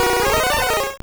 Cri de Crustabri dans Pokémon Or et Argent.